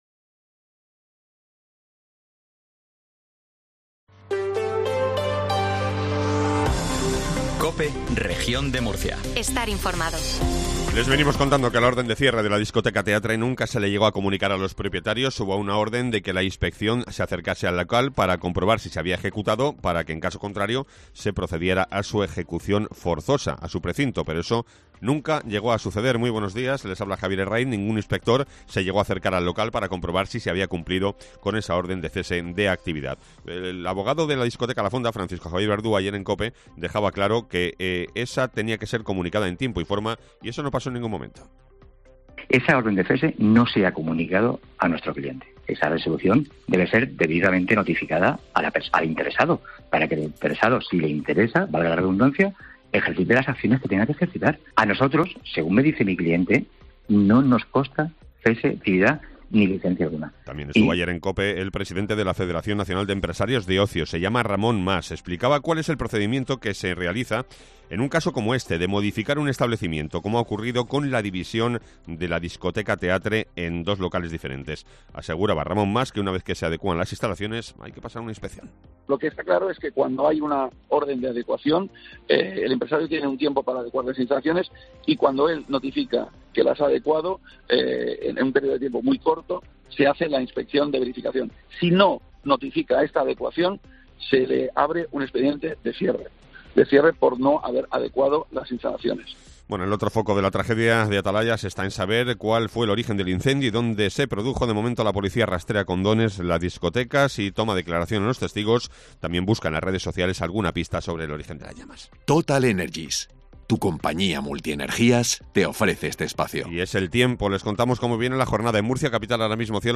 INFORMATIVO MATINAL REGION DE MURCIA 0750